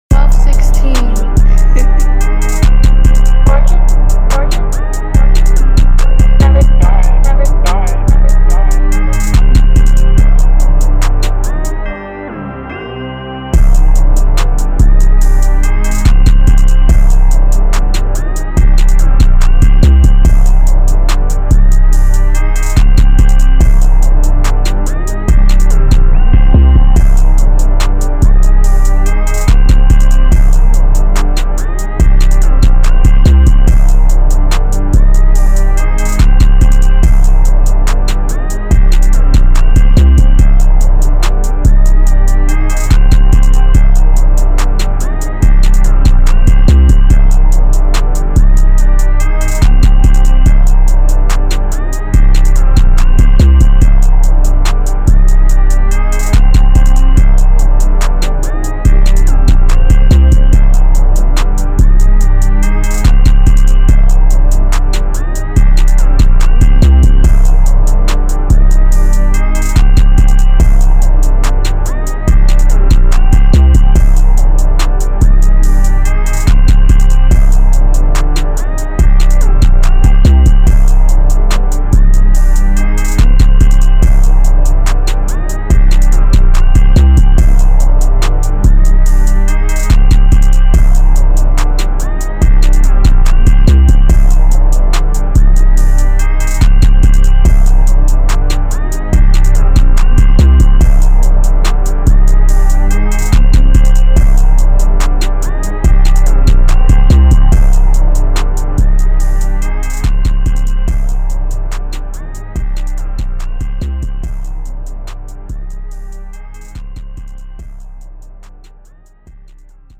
official instrumental